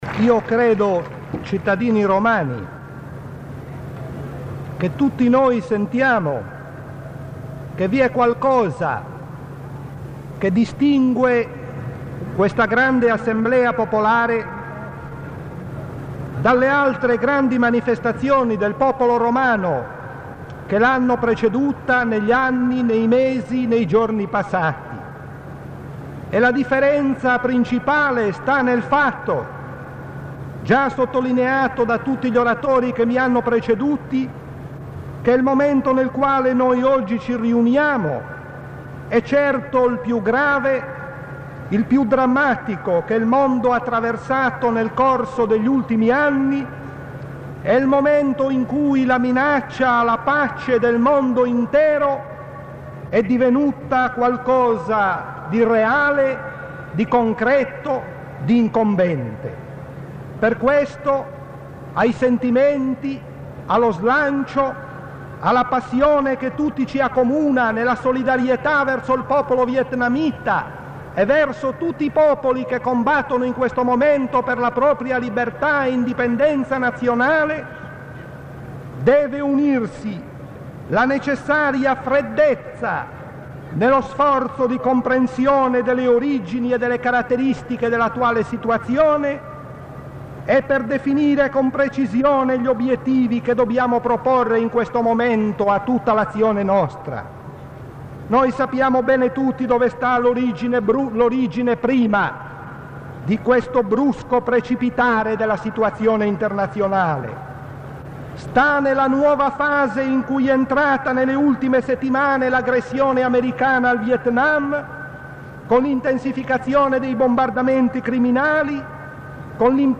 Sul Vietnam – Estratti dal comizio per il Vietnam a piazza Navona, Roma 24 maggio 1967